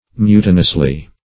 Meaning of mutinously. mutinously synonyms, pronunciation, spelling and more from Free Dictionary.
mutinously.mp3